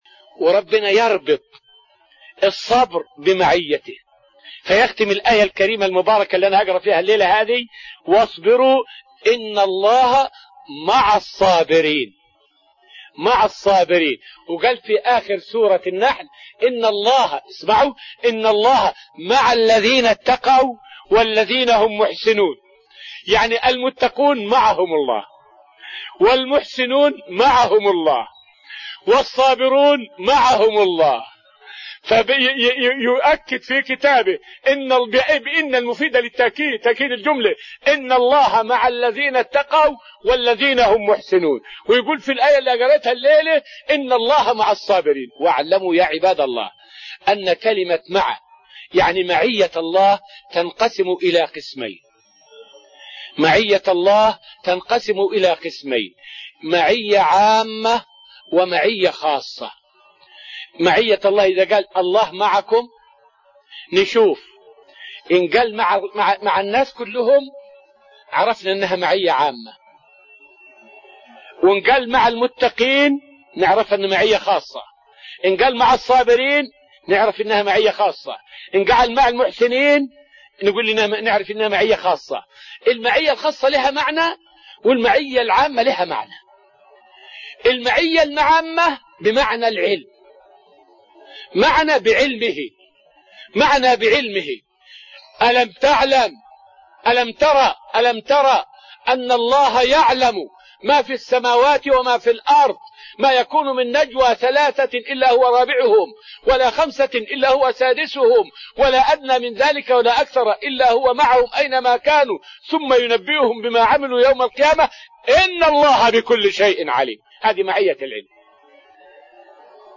فائدة من الدرس العاشر من دروس تفسير سورة الأنفال والتي ألقيت في رحاب المسجد النبوي حول أن تفسير {إن الله معنا} معية الله للنبي صلى الله عليه وسلم.